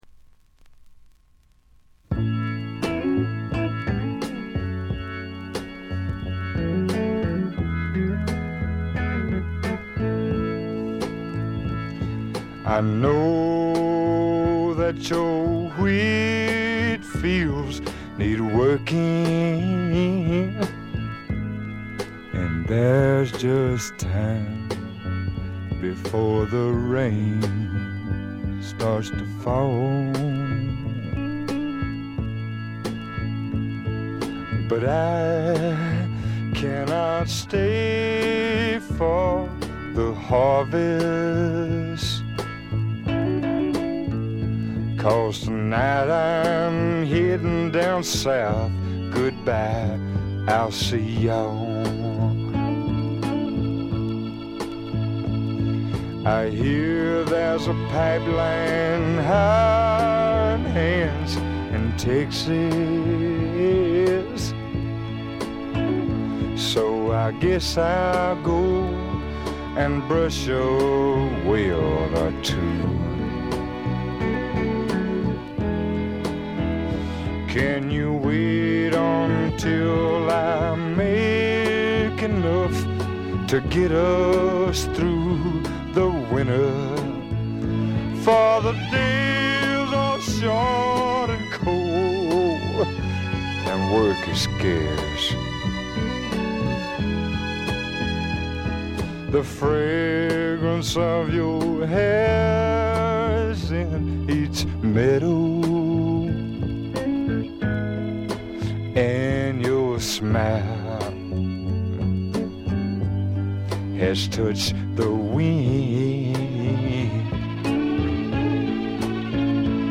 軽微なバックグラウンドノイズ、散発的なプツ音が数か所。
ハードなファンキースワンプから甘いバラードまで、メンフィス録音スワンプ基本中の基本ですね。
試聴曲は現品からの取り込み音源です。
guitar, harmonica